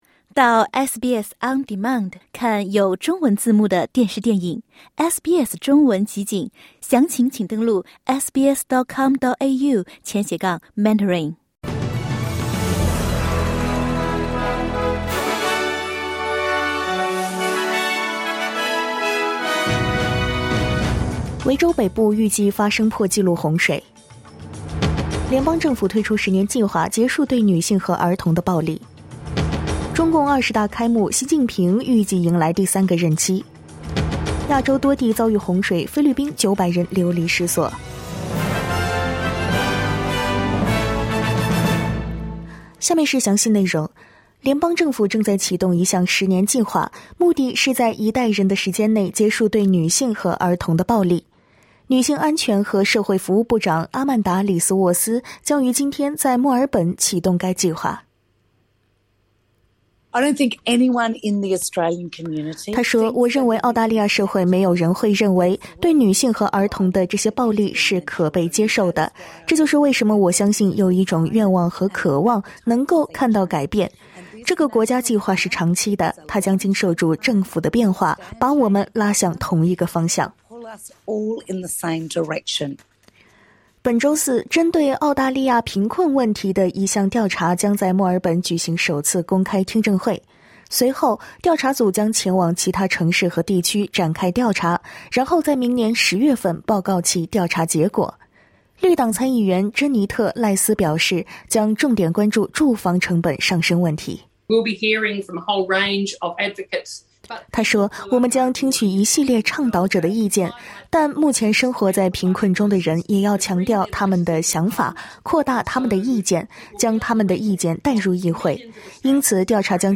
SBS早新闻（10月17日）